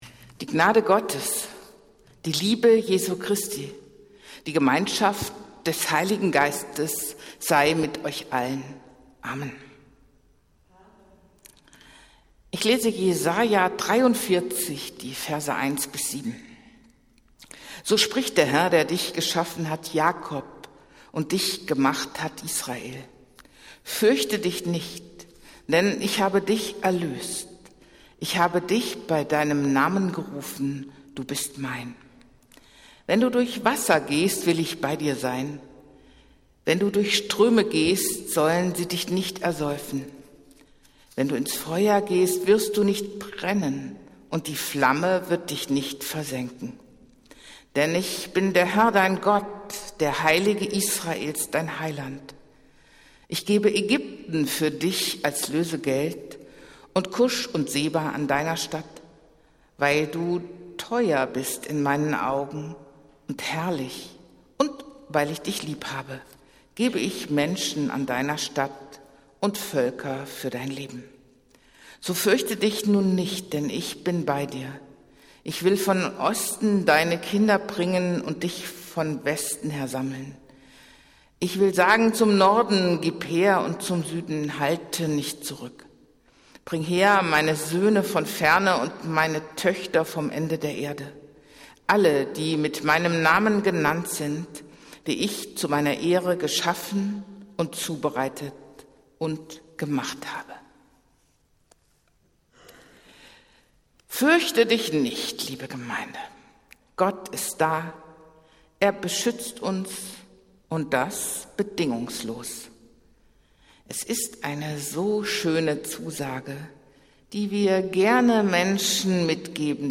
Predigt des Gottesdienstes aus der Zionskirche am Sonntag, den 16. Juli 2023